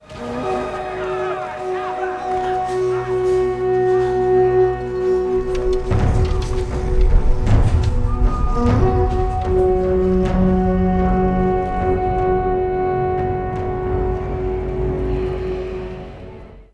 Horn of Rohan
rohan_horn.wav